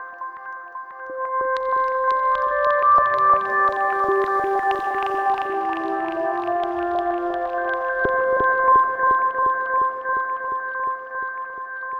синтезатор звуки скачать, слушать онлайн ✔в хорошем качестве